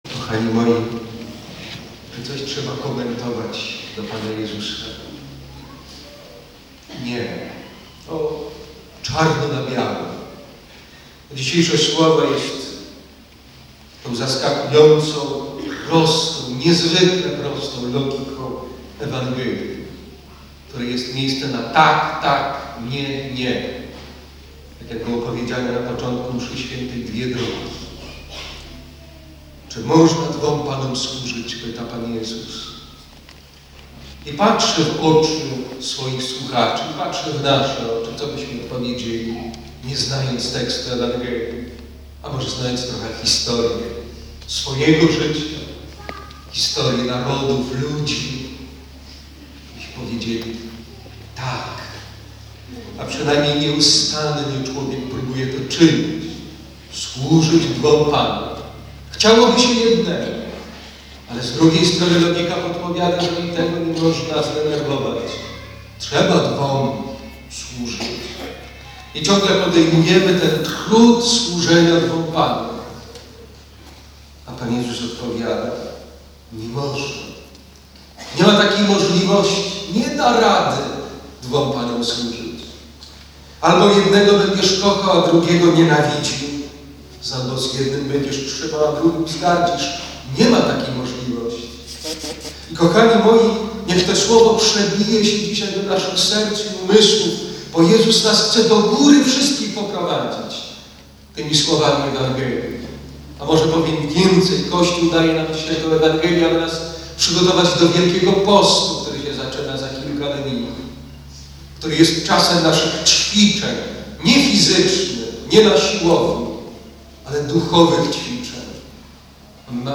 Msza św. suma